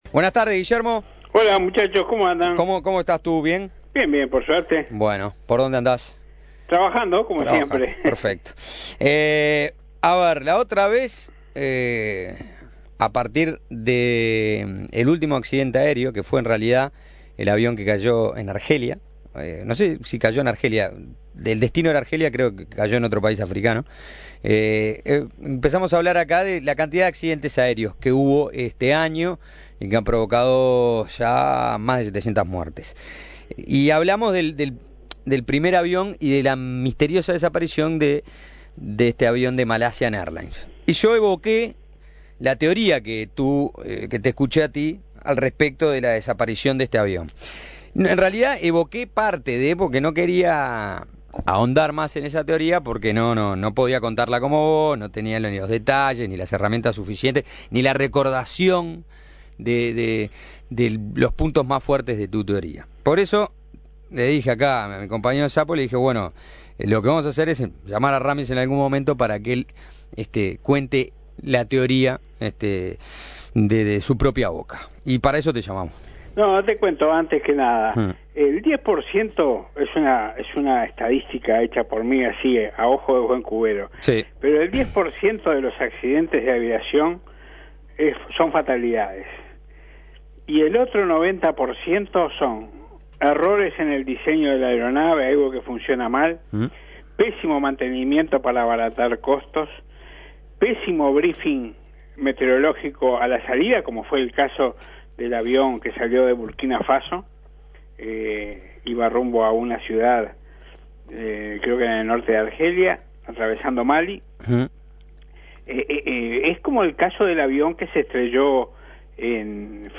dialogó con Suena Tremendo y expuso sus hipótesis acerca del incidente.